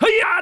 attack_1c.wav